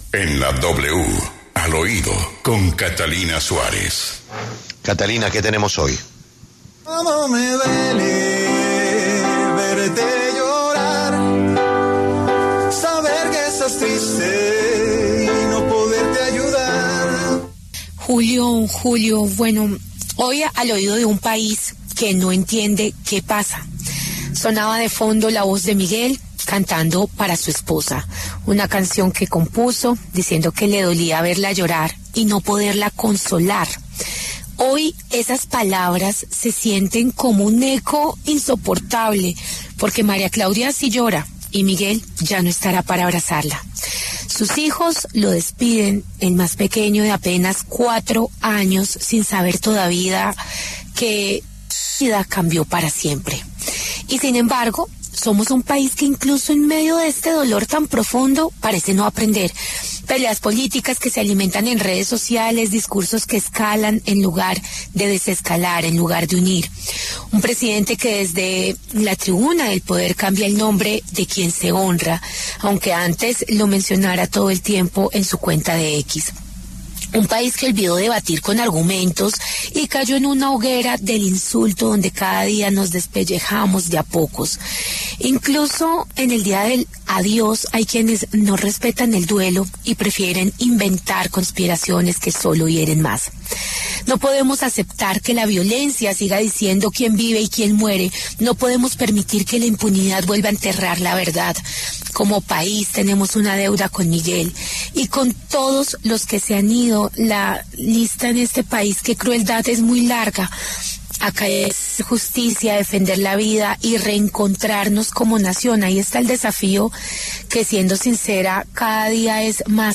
Inicia Al Oído con la canción que compuso Miguel Uribe, cantando para su esposa.